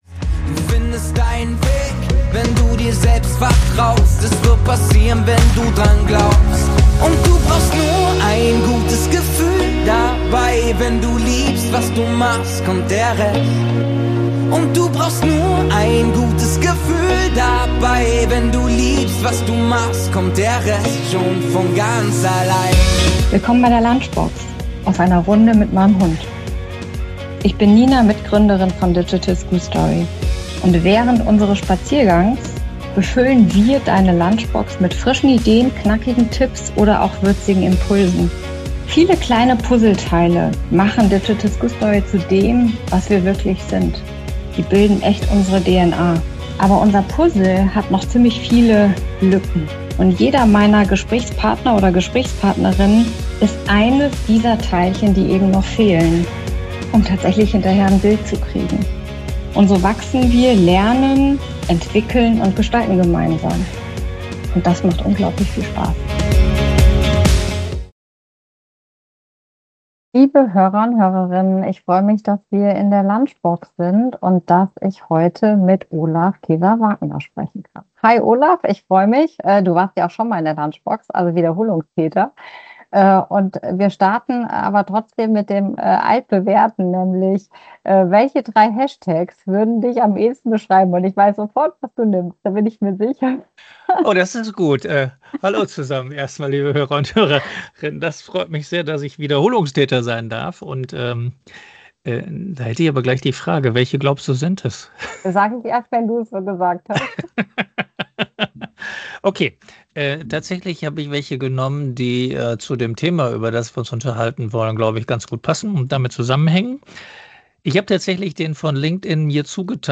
Ein inspirierendes Gespräch über das Fragen als Haltung, als Werkzeug und als Weg, Zukunft aktiv zu gestalten.